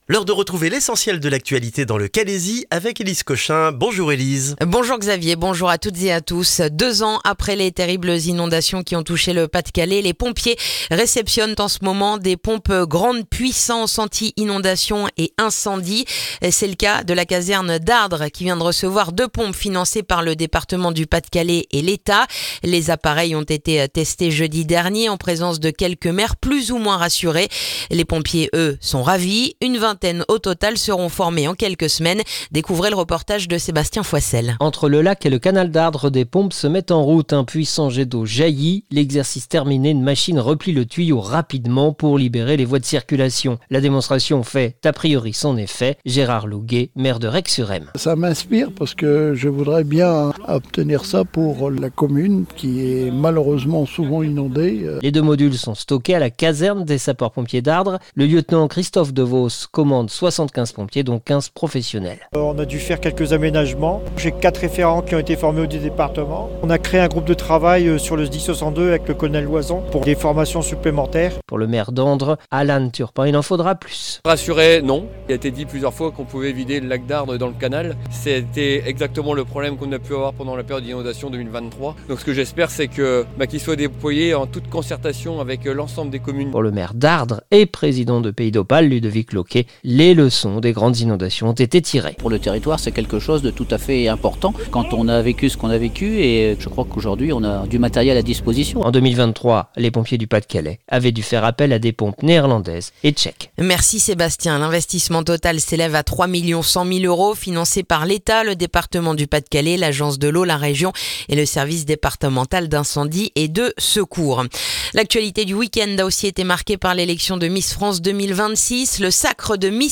Le journal du lundi 8 décembre dans le calaisis